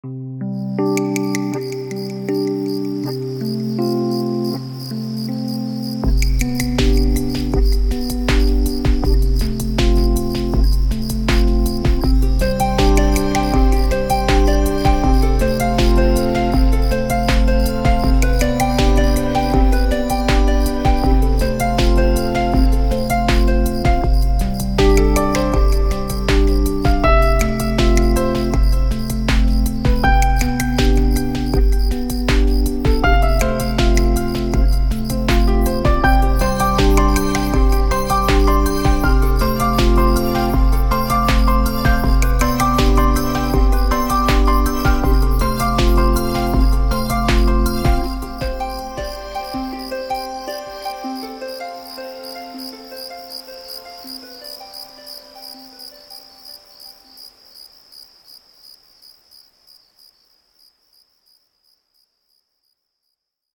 Cicada.ogg